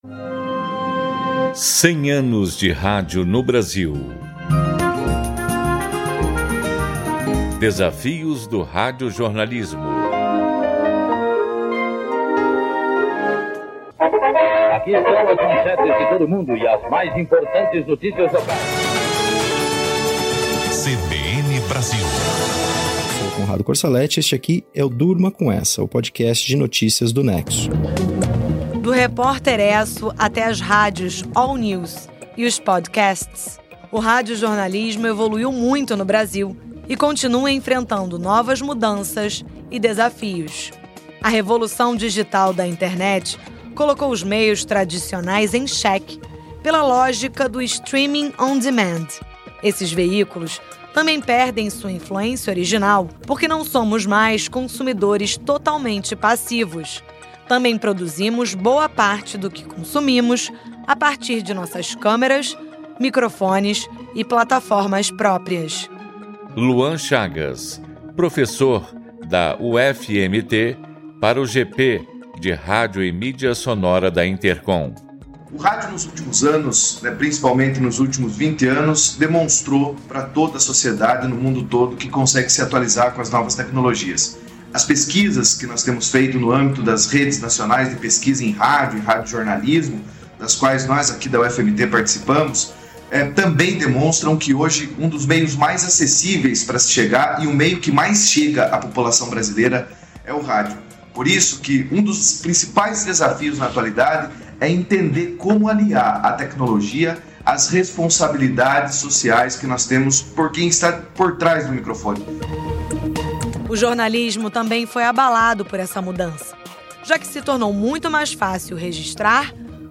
Até 7 de setembro, a Rádio MEC vai produzir e transmitir, diariamente, interprogramas com entrevistas e pesquisas de acervo sobre diversos aspectos históricos relacionados ao veículo.